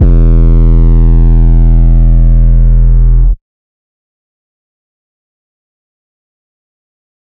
DMV3_808 4.wav